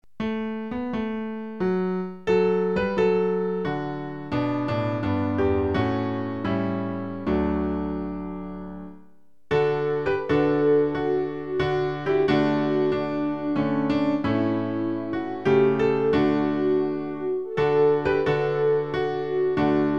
Klavier-Playback zur Begleitung der Gemeinde
(ohne Gesang)